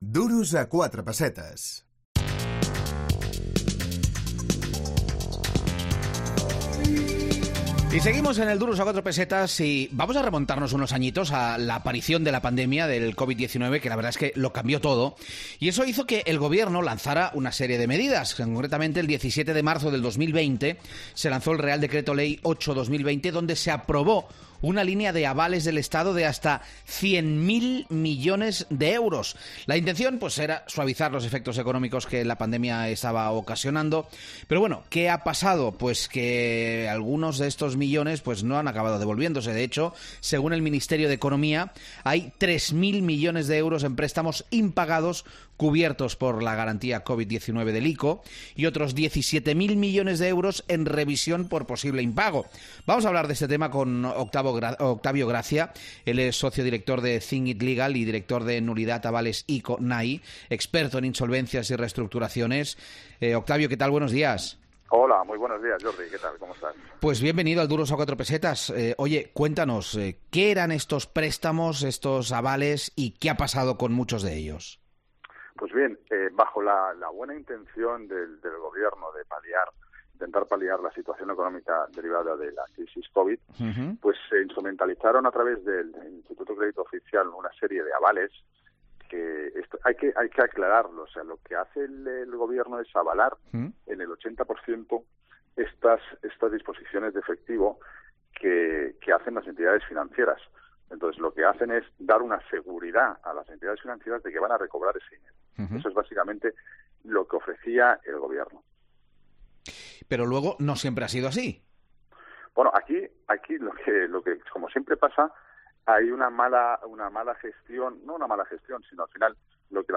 AUDIO: Hablamos con los expertos en insolvencias de "Think it Legal"